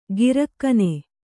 ♪ girakkane